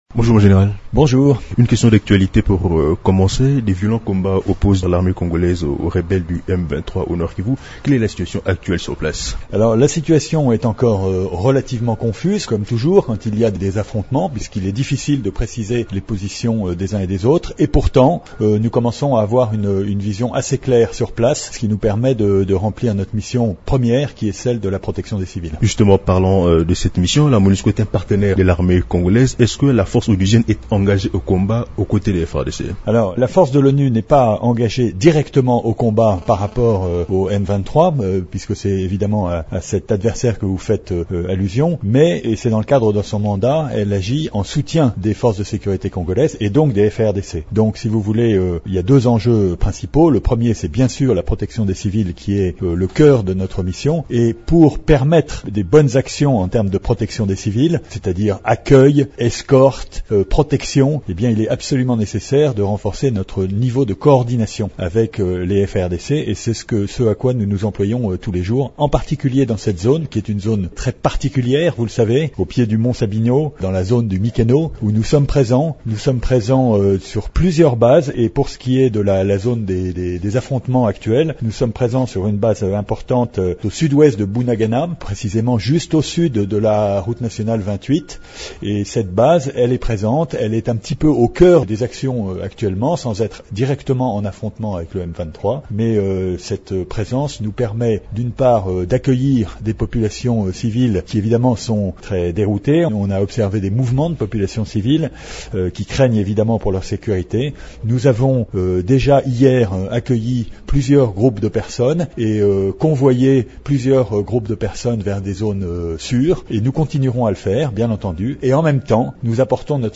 Son commandant second, le Général Benoît Chavanat souligne que la force de la MONUSCO accorde une grande importance à la protection des civils dans cette zone de combats où on enregistre des déplacements des populations.